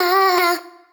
SG - Vox 2.wav